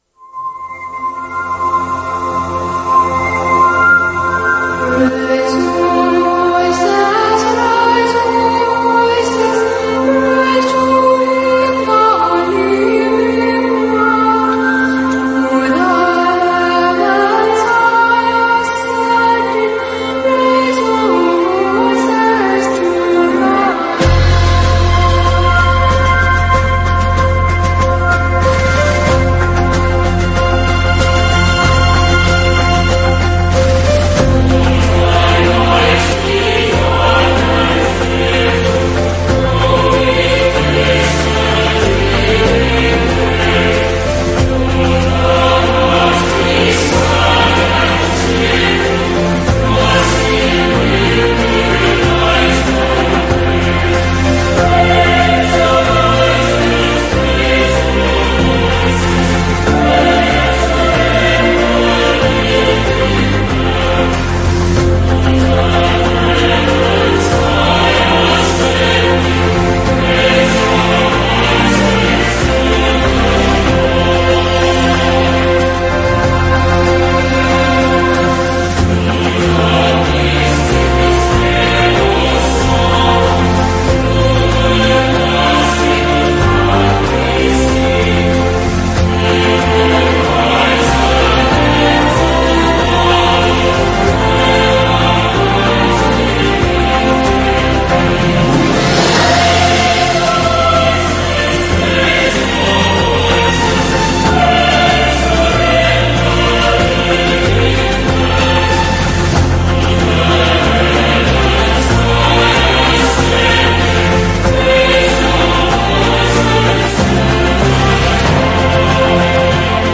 ospite in diretta